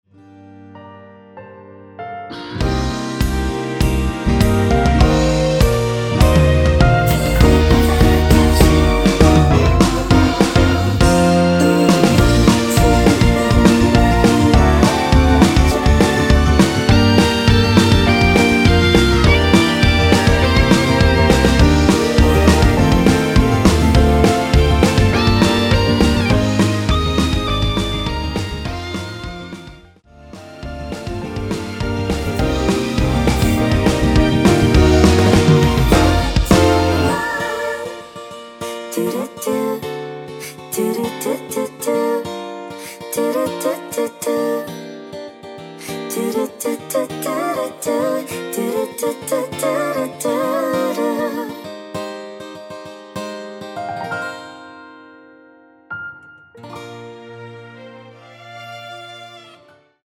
원키에서(+4)올린 코러스 포함된 MR입니다.
Bb
앞부분30초, 뒷부분30초씩 편집해서 올려 드리고 있습니다.
중간에 음이 끈어지고 다시 나오는 이유는